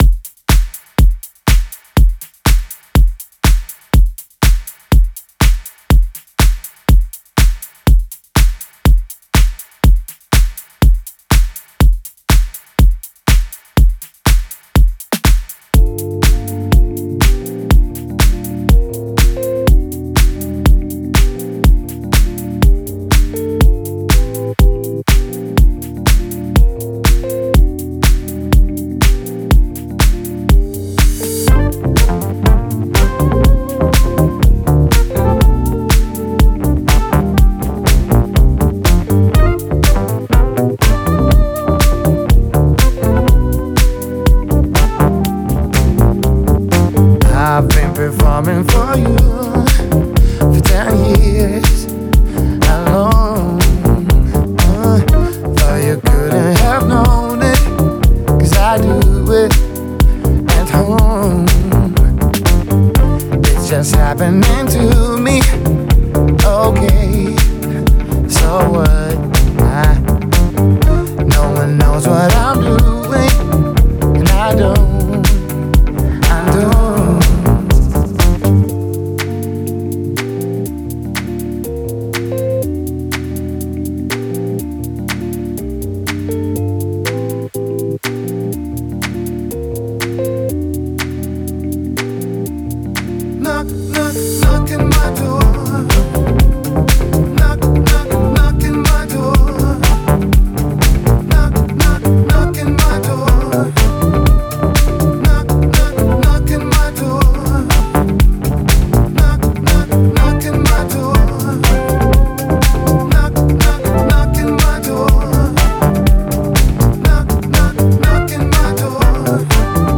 атмосферная электронная композиция
которая сочетает элементы house и pop.